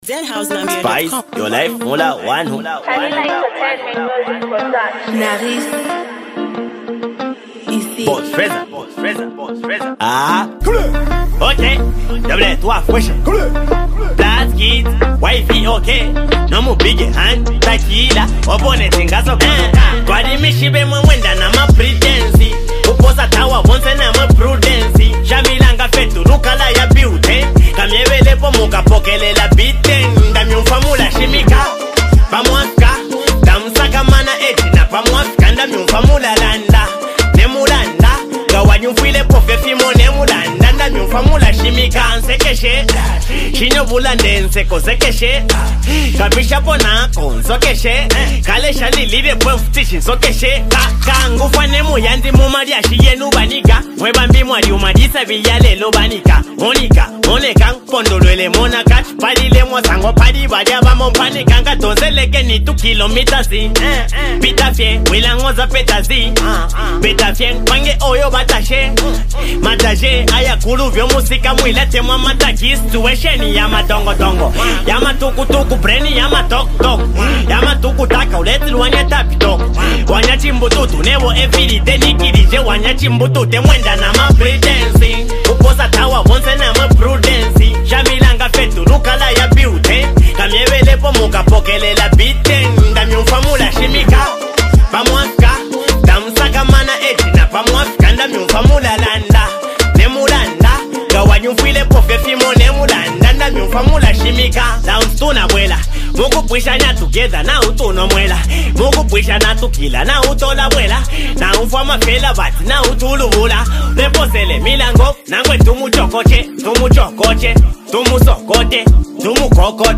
delivers hard beats, street vibes, and unstoppable flow.